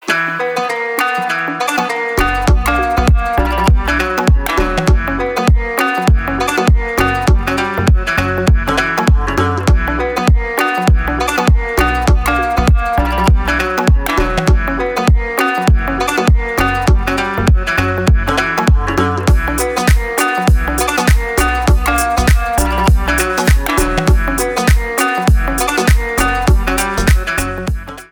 Поп Музыка
кавказские # без слов